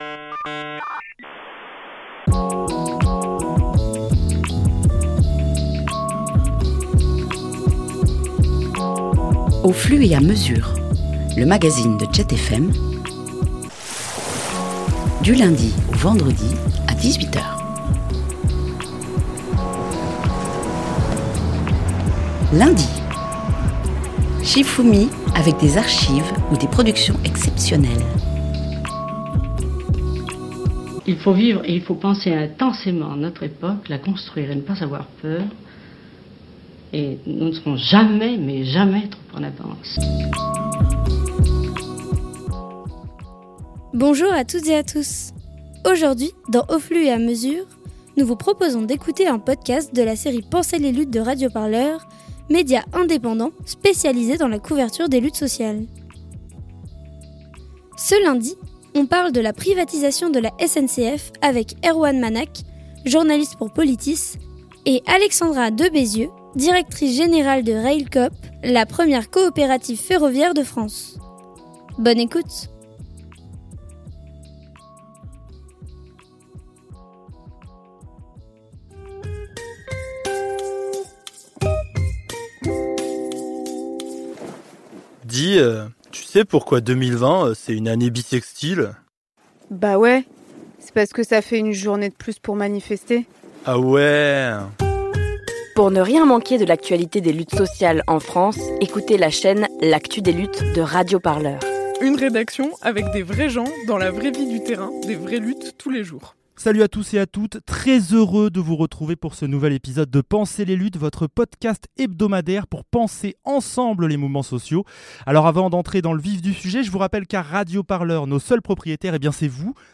Dans cette série, on interroge des intellectuel-les, des militant-es chevronné-es, des artistes ou encore des chercheur-euses pour parler des luttes de France et d’ailleurs.